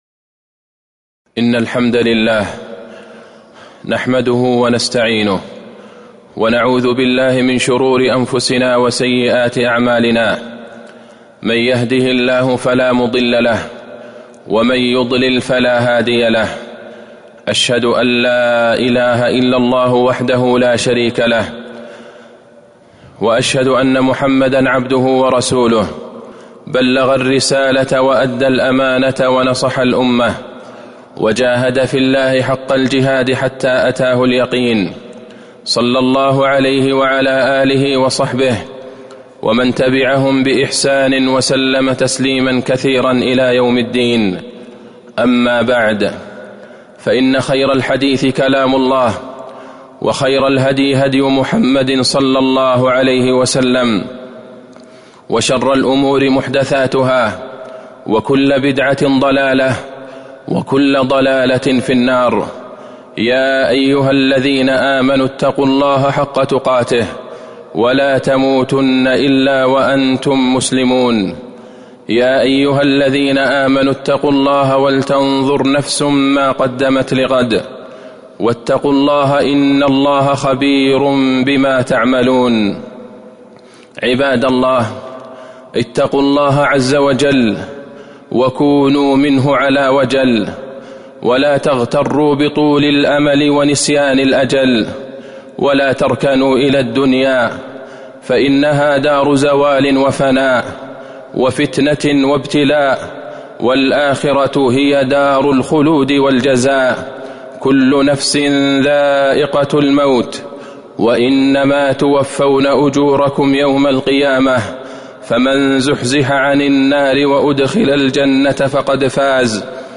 تاريخ النشر ٢٥ جمادى الآخرة ١٤٤٣ هـ المكان: المسجد النبوي الشيخ: فضيلة الشيخ د. عبدالله بن عبدالرحمن البعيجان فضيلة الشيخ د. عبدالله بن عبدالرحمن البعيجان الإيمان باليوم الآخر